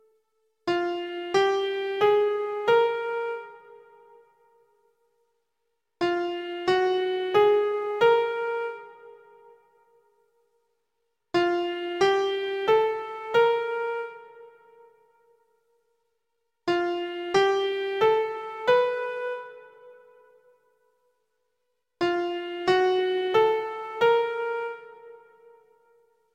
Part 2 Tetrachord Patterns
One each WWH, WHW, HWW, WWW, H(W+H)H